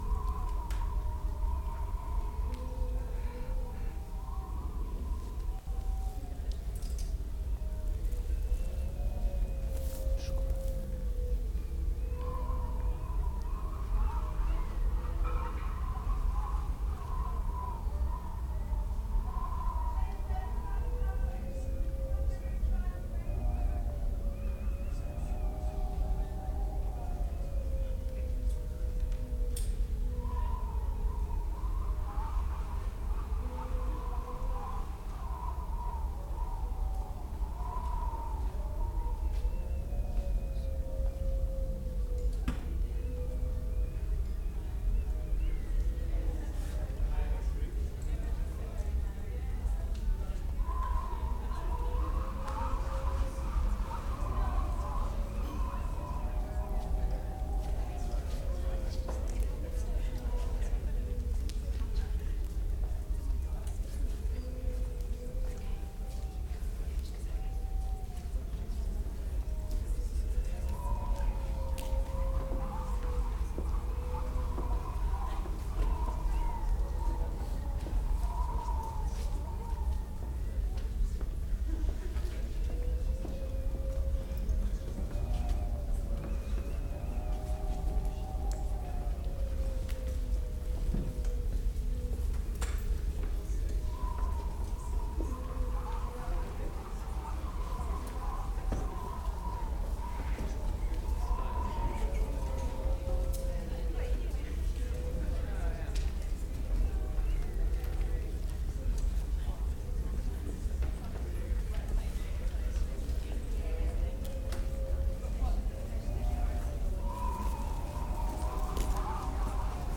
A performance of Ueinzz’s new play.